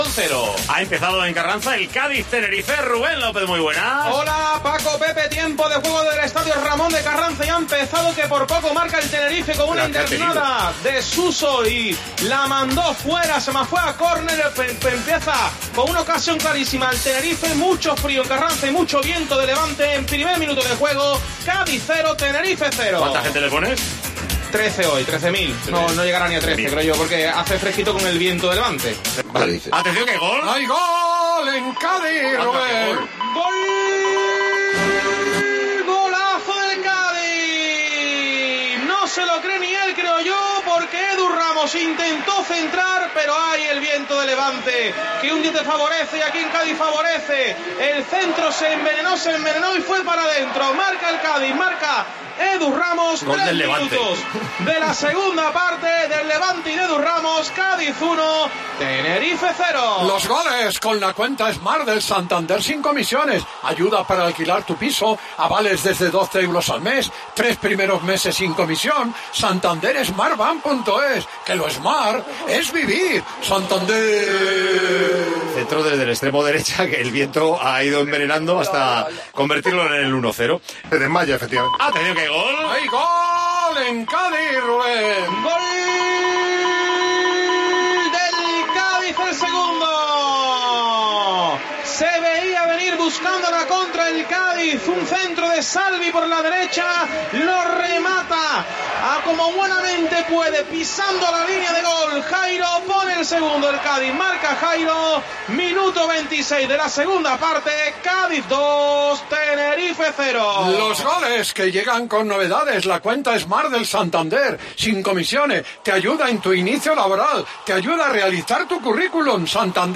Los goles del Cádiz 2-0 Tenerife en Tiempo de Juego